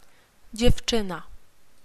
By clicking links you can listen to these useful Polish words and phrases being spoken by native speakers of Polish.